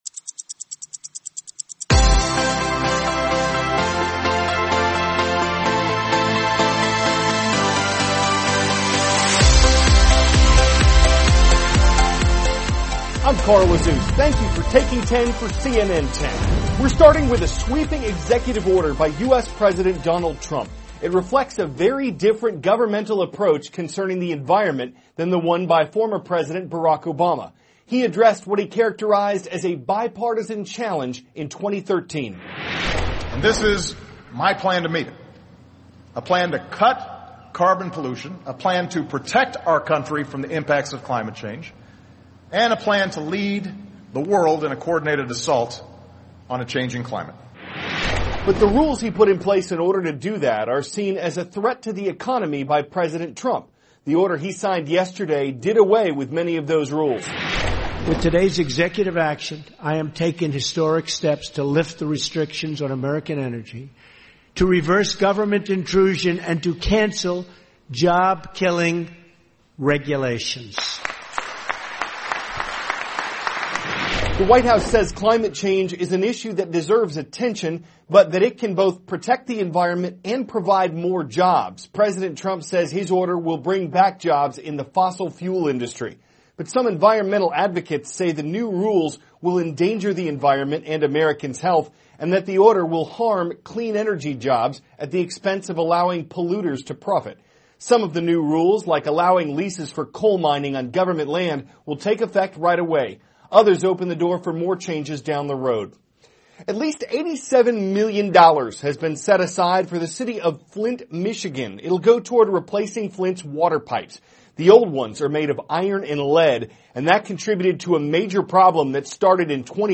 (cnn Student News) -- March 29, 2017 Trump`s Executive Order Concerning the Environment; A Settlement Concerning Flint City`s Water Crisis; A Potentially Protective Type of Gel THIS IS A RUSH TRANSCRIPT.